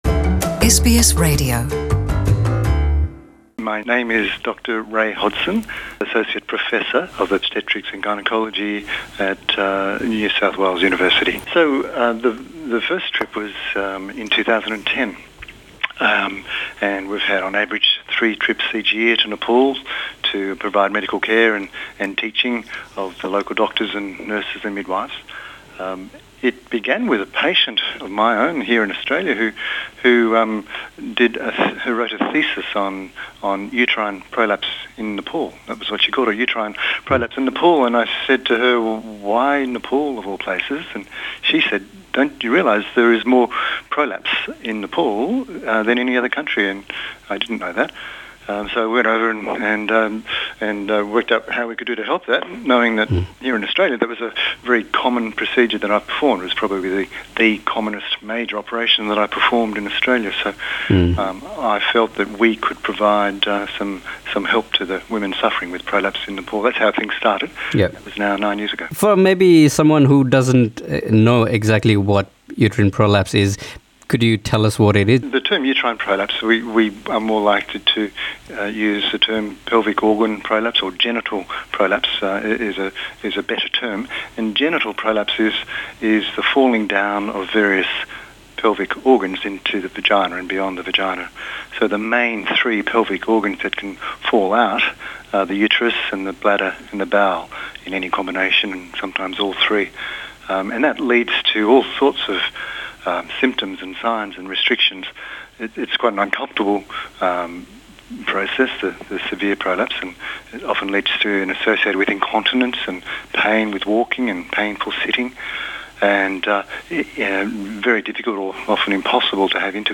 “हार्टब्रेक इन दि हिमालयज” र यसै सेरोफेरोमा हामीले उनीसँग गरेको कुराकानी।